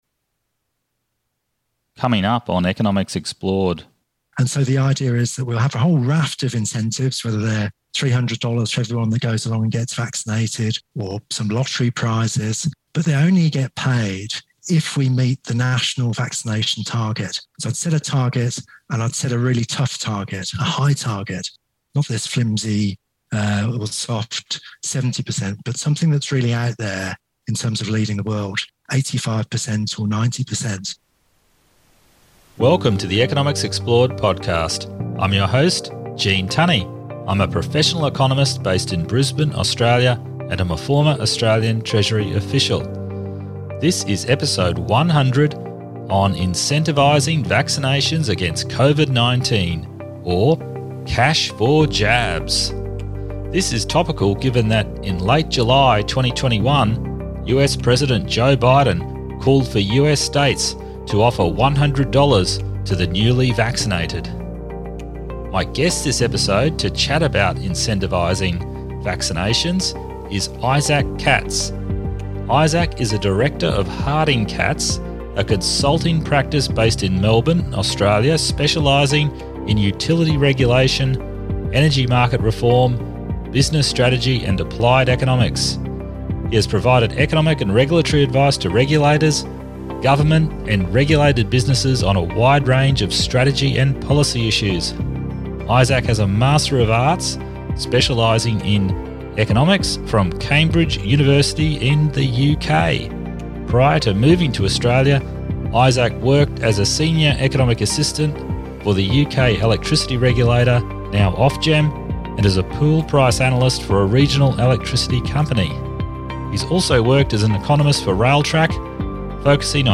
a detailed discussion on shrinkflation